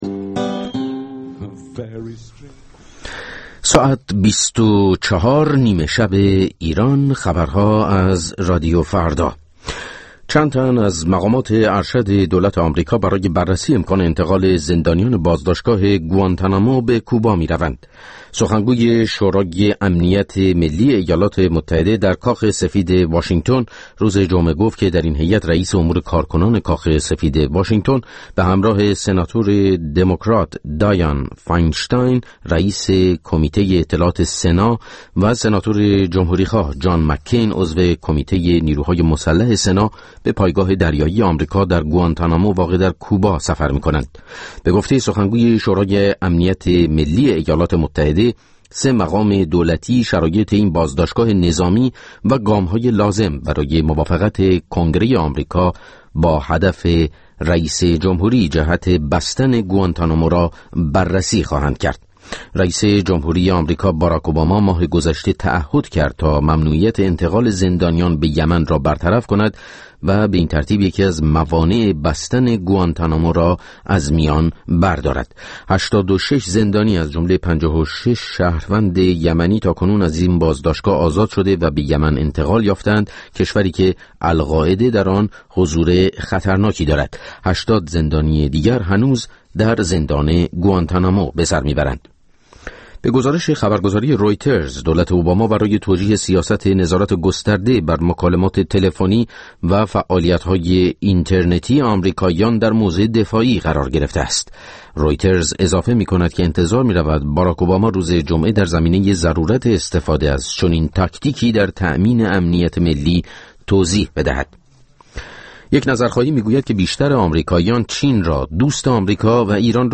کنسرت «اميد برای ايران» با رادیو فردا